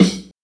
BRK_SNR.wav